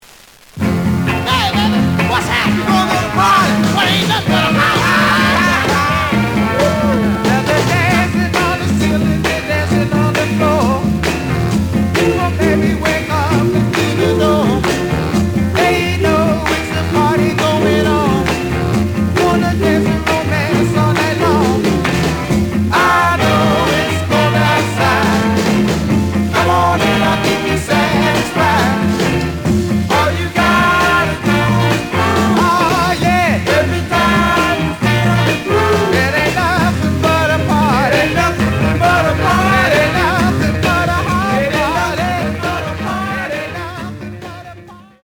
The audio sample is recorded from the actual item.
●Genre: Soul, 60's Soul
Looks good, but some noise on A side.)